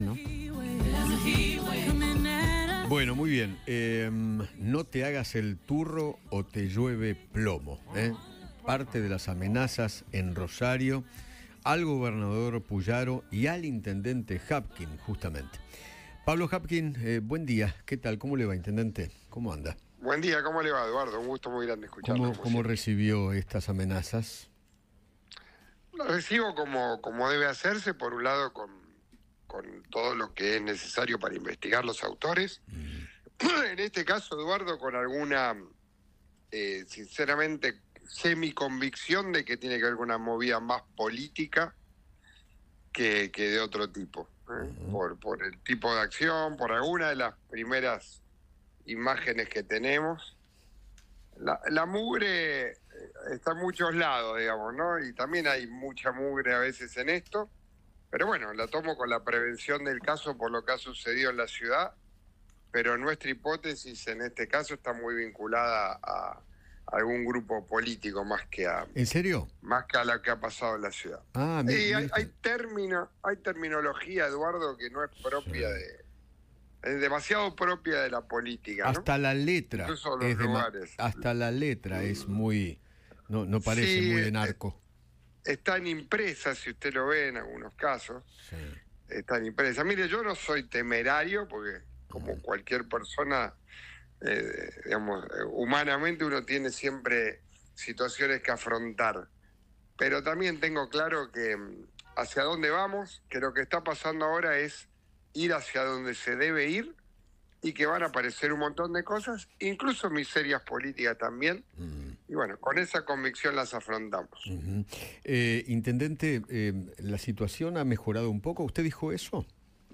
Pablo Javkin, intendente de Rosario, conversó con Eduardo Feinmann sobre las amenazas de muerta que recibió, al igual que el Gobernado Maximiliano Pullaro.